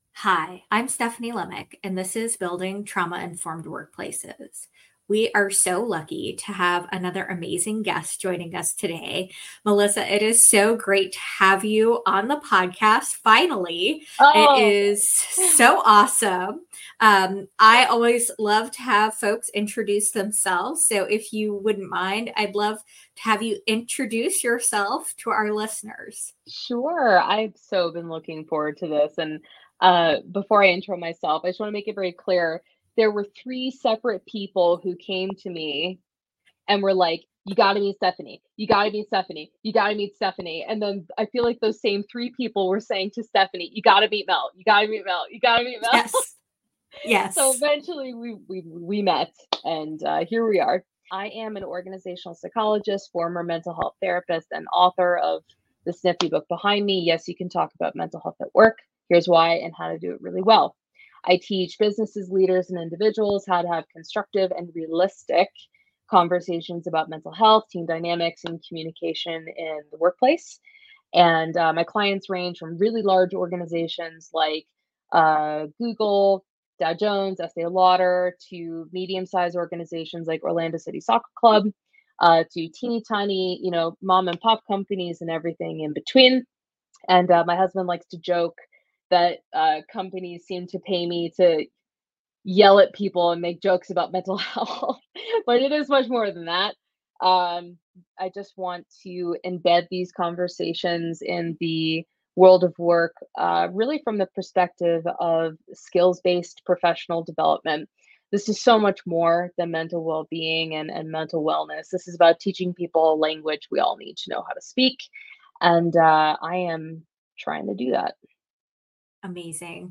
Join us for a conversation on mental health at work with one of the leading voices on this topic